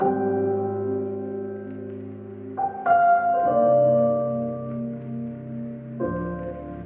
描述：麦克米勒的爵士类型罗德斯钢琴
Tag: 130 bpm Trap Loops Rhodes Piano Loops 2.48 MB wav Key : Unknown FL Studio